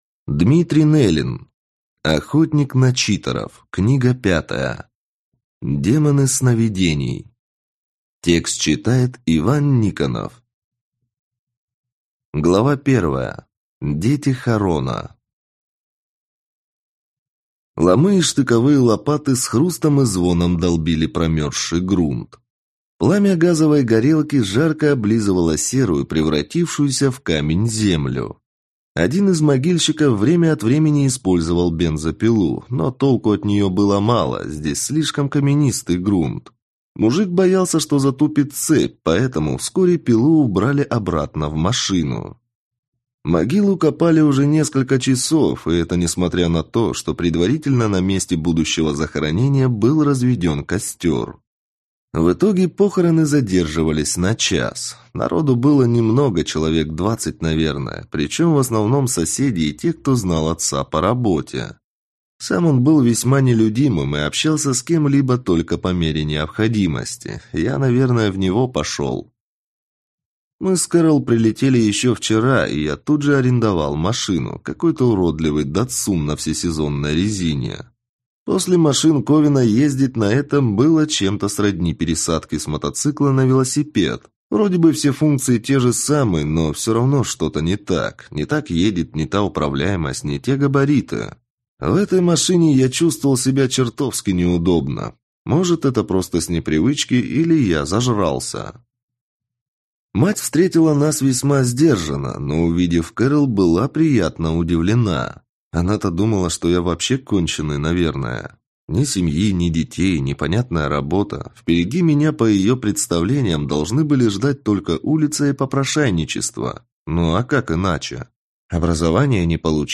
Аудиокнига Демоны сновидений | Библиотека аудиокниг
Прослушать и бесплатно скачать фрагмент аудиокниги